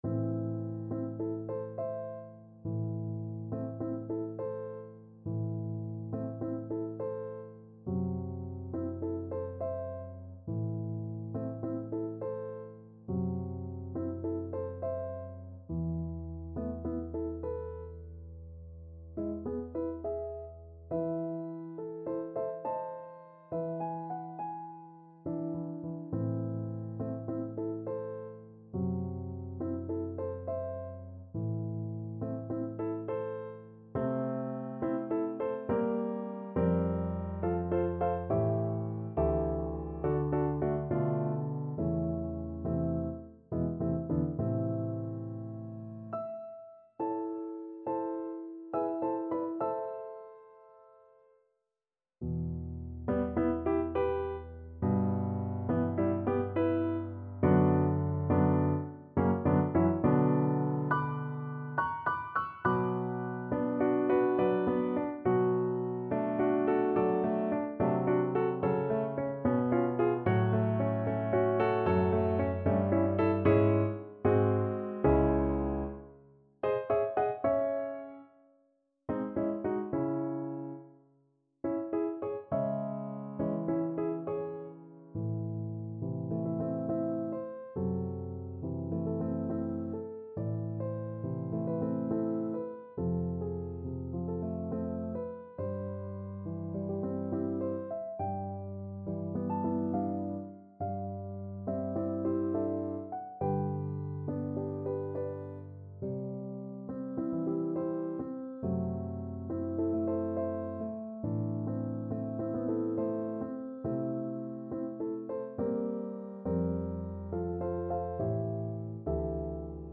Andante =69
Classical (View more Classical Violin Music)